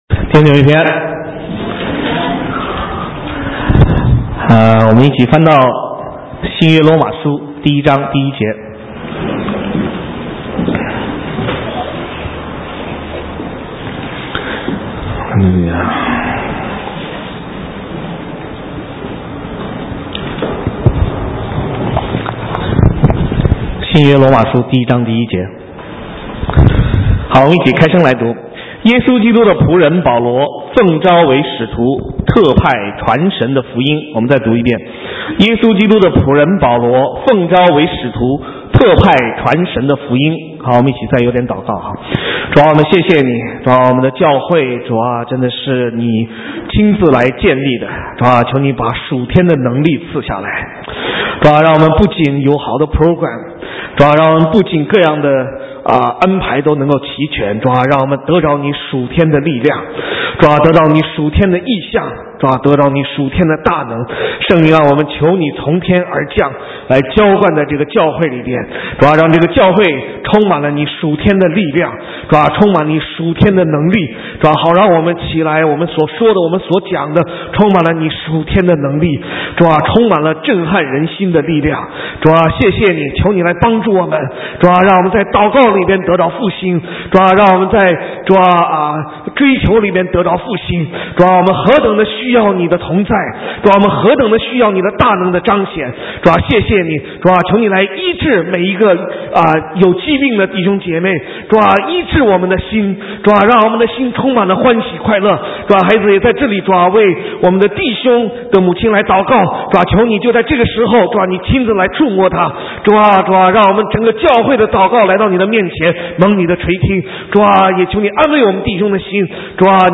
神州宣教--讲道录音 浏览：最伟大的心态 (2011-01-23)